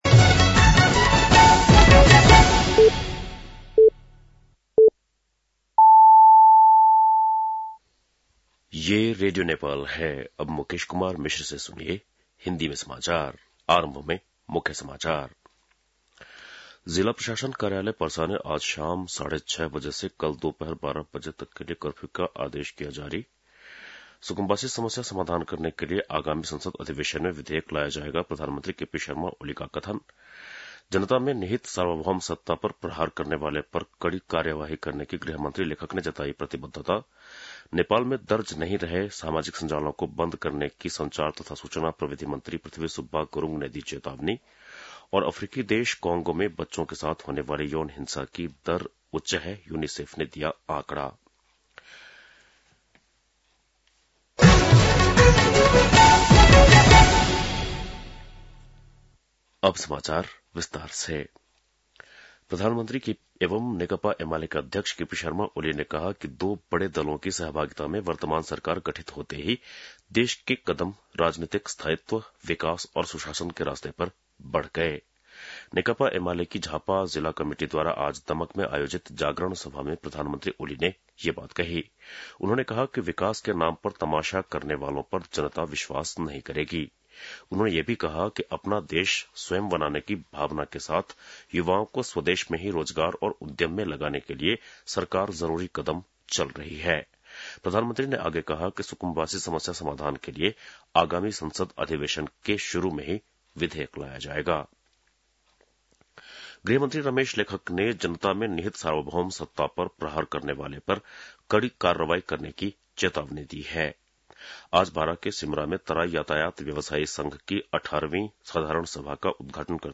बेलुकी १० बजेको हिन्दी समाचार : ३० चैत , २०८१
10-pm-News.mp3